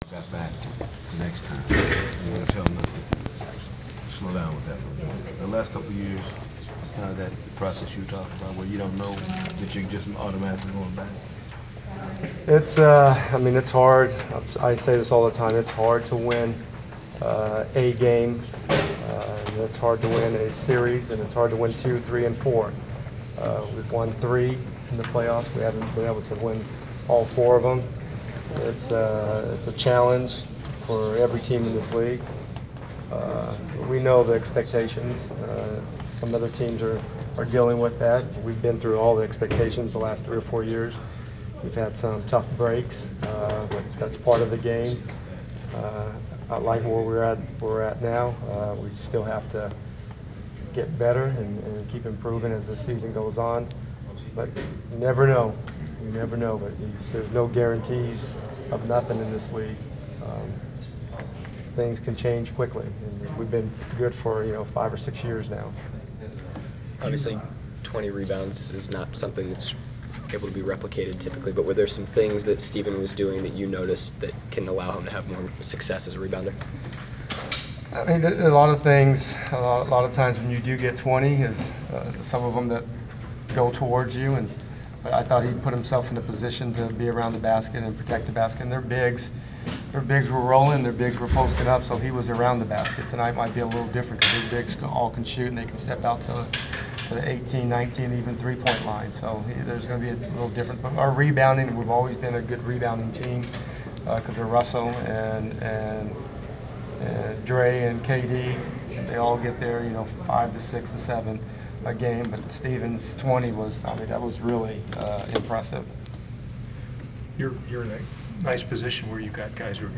Inside the Inquirer: Pregame presser with Oklahoma City Thunder Head Coach Scott Brooks (1/23/15)
We attended the pregame presser of Oklahoma City Thunder head coach Scott Brooks before his team’s road game against the Atlanta Hawks on Jan. 23. Topics included the overall state of the Thunder and strong play of Atlanta.